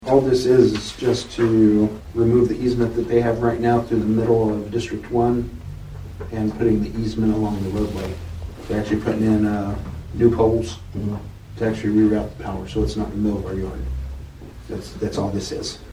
Chairman Paul Crupper discusses PSO's project.
Crupper on PSO Easement.mp3